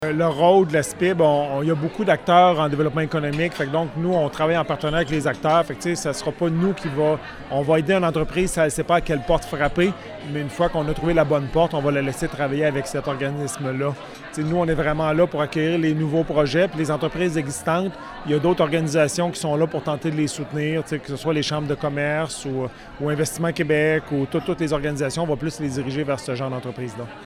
lors d’un colloque organisé mardi par la Chambre de commerce et de l’industrie du Cœur-du-Québec.